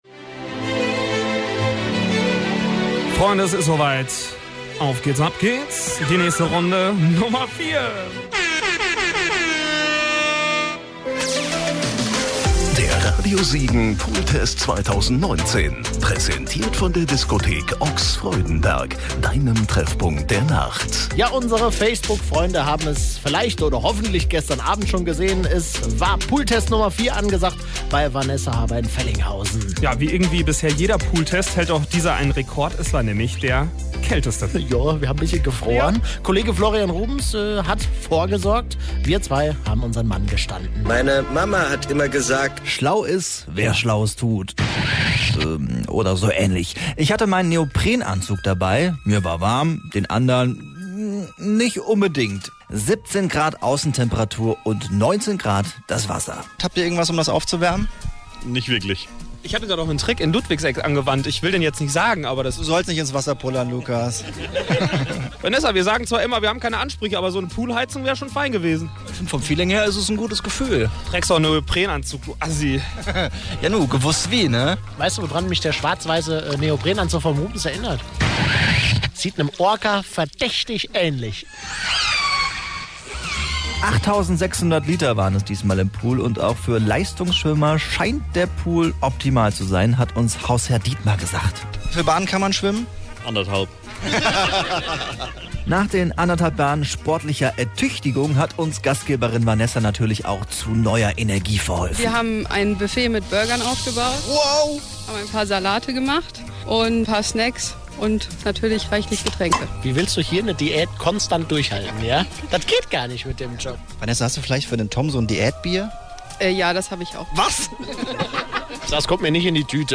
lufe---mitschnitt-pooltest-4.mp3